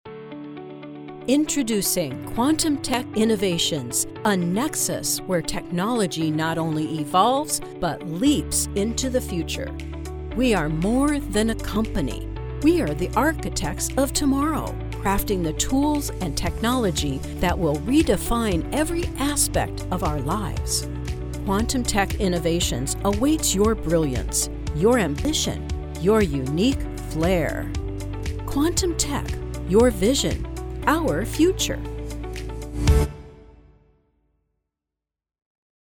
Professionally trained voice artist with a naturally refined and sophisticated delivery.
Quantum Tech - technology, business presentation, knowledgeable, presenter, confident, engaging, natural, warm